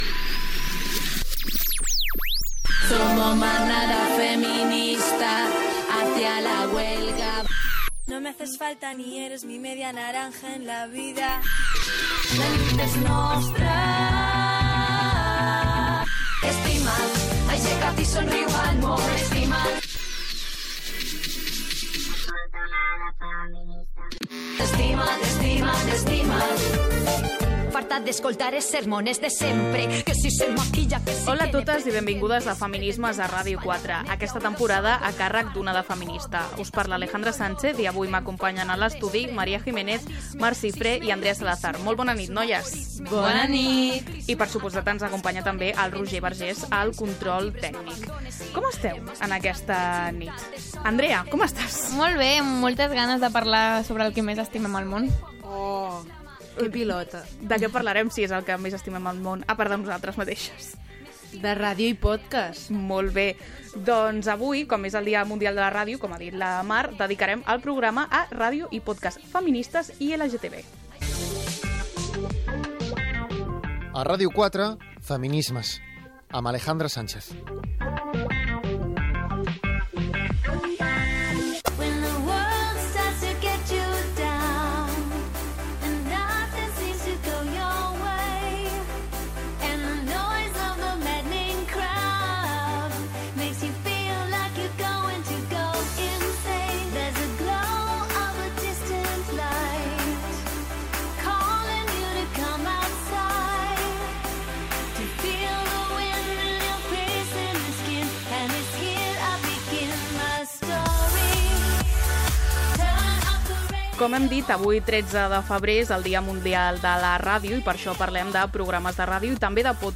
Careta del programa, tema i equip, identificació, la ràdio i els pòdcasts feministes i LGTBI , amb motiu del Dia Mundial de la Ràdio.
Divulgació
FM